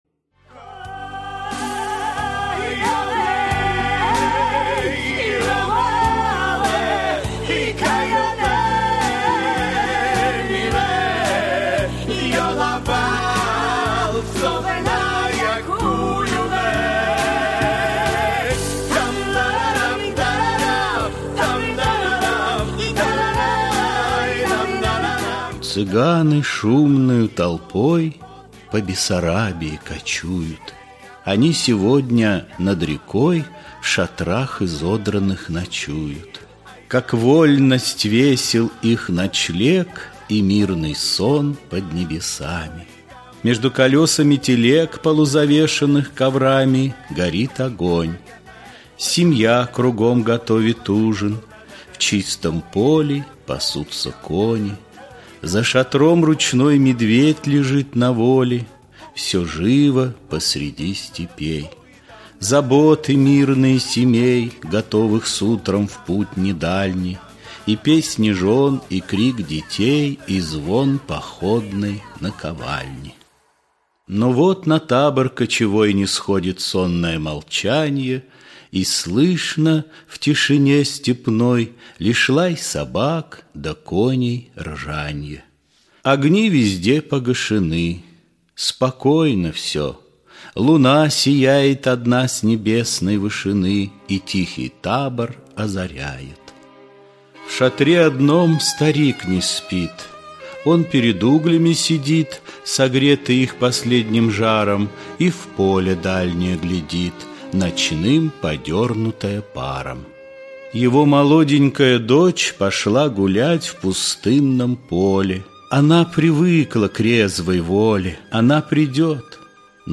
Аудиокнига Цыганы
Качество озвучивания весьма высокое.